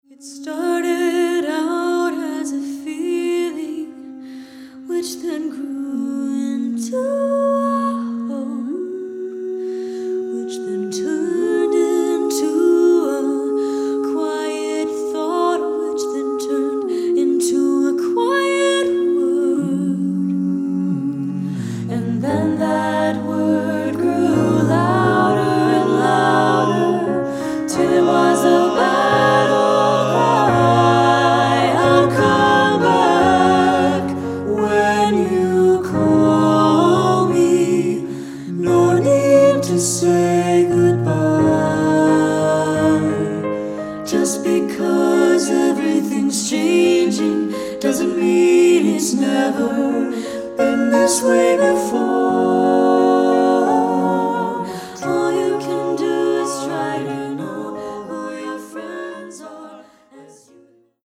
Choral Movie/TV/Broadway
SATB